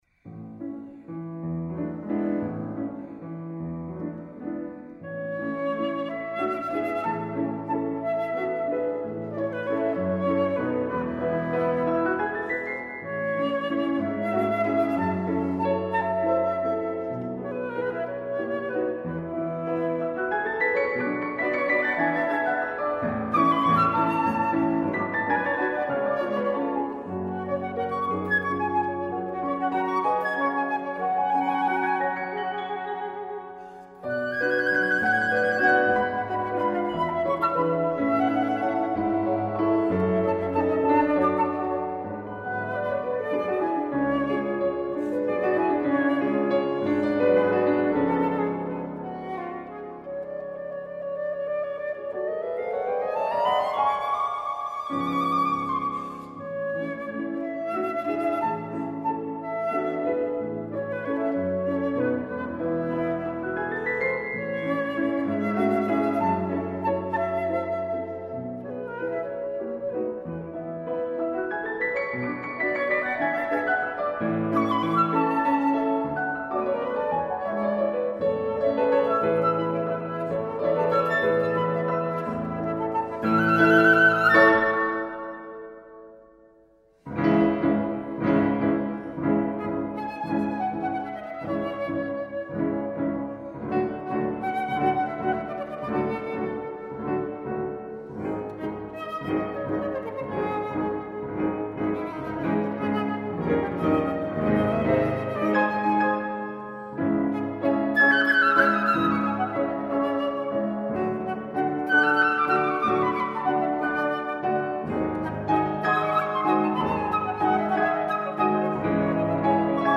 für Flöte und Klavier